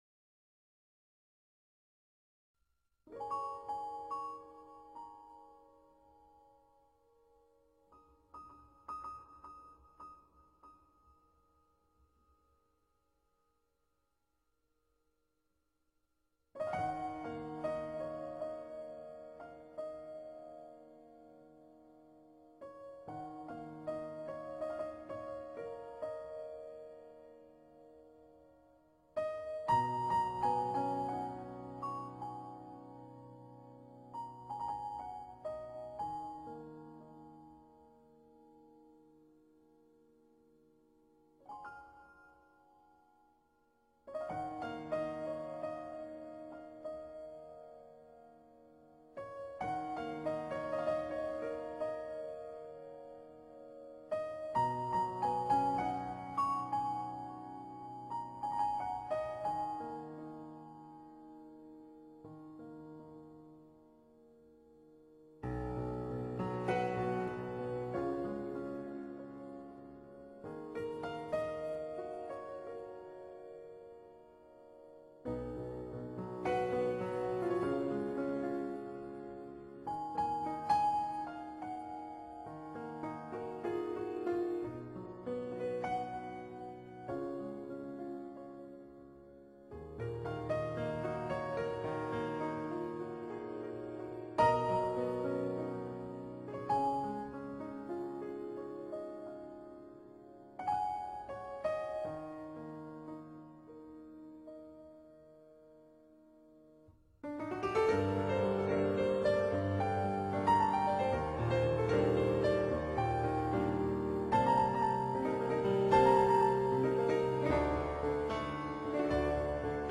Файл в обменнике2 Myзыкa->Джаз
исполняющая музыку в стиле нью эйдж и джаз.